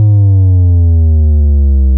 All New Zip File And New Dj Sample Pack